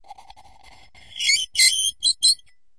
玻璃 陶瓷 " 陶瓷冰淇淋碗金属勺子在碗内发出刺耳的声音 06
描述：用金属勺刮擦陶瓷冰淇淋碗的内部。 用Tascam DR40录制。
Tag: 刮下 金属勺 尖叫 刮去 尖叫 陶瓷 金属